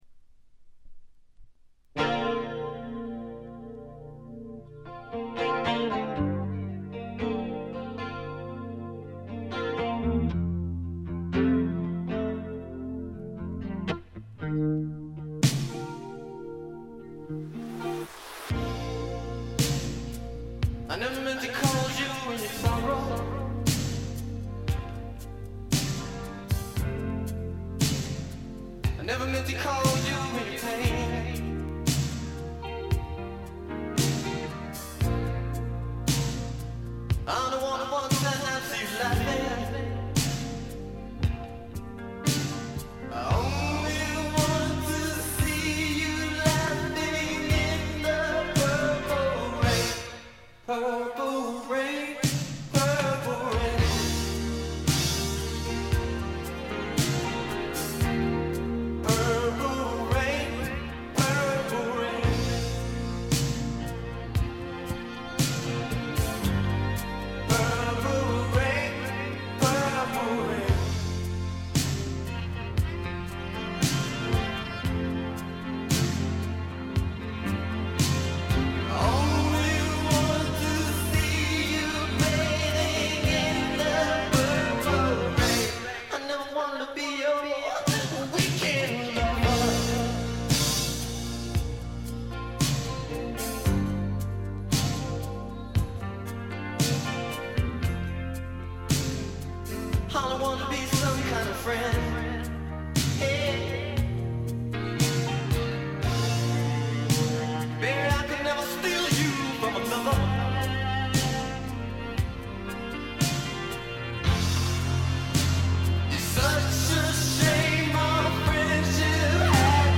試聴曲は現品からの取り込み音源です。
Strings, Cello ?
Strings, Violin ?